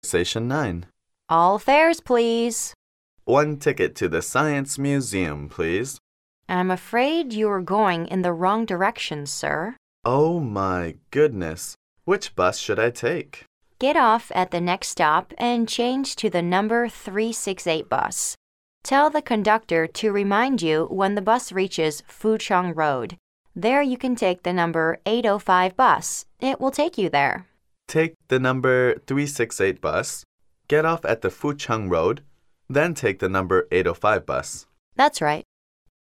Conversation 9